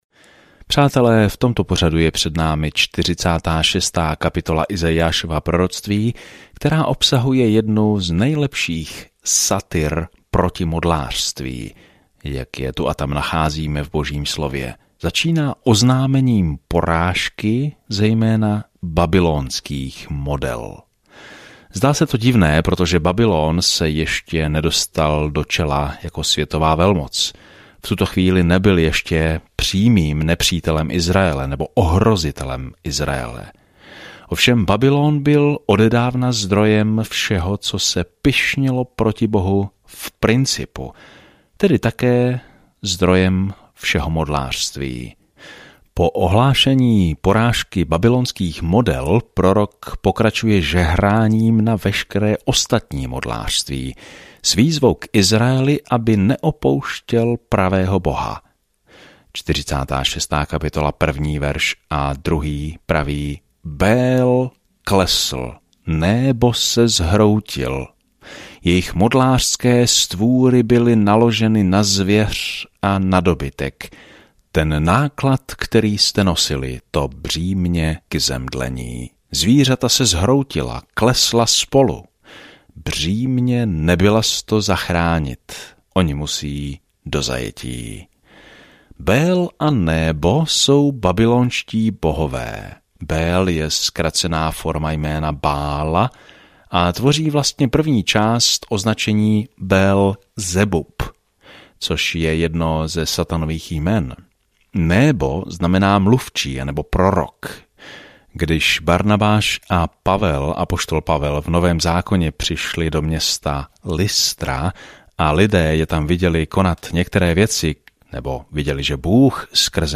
Písmo Izaiáš 44:17-28 Izaiáš 45 Izaiáš 46 Den 50 Začít tento plán Den 52 O tomto plánu Izajáš, nazývaný „páté evangelium“, popisuje přicházejícího krále a služebníka, který „ponese hříchy mnohých“ v temné době, kdy Judu dostihnou političtí nepřátelé. Denně procházejte Izajášem a poslouchejte audiostudii a čtěte vybrané verše z Božího slova.